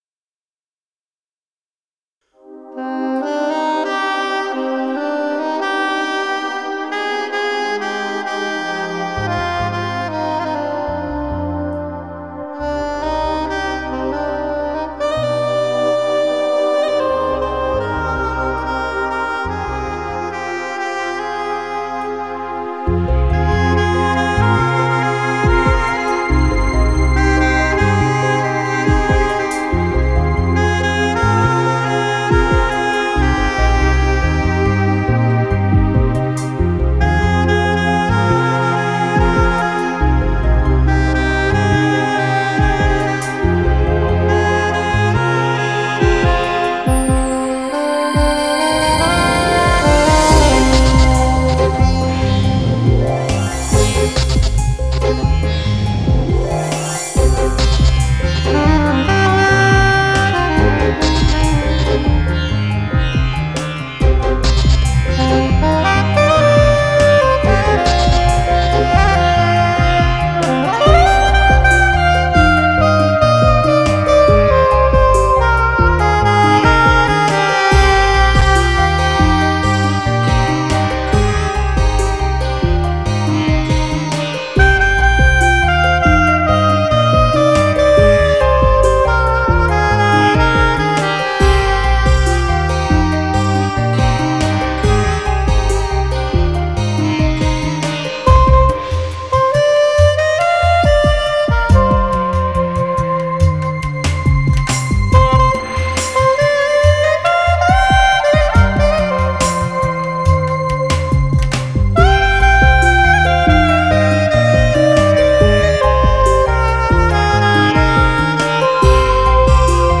Genre: Balkan Folk, World Music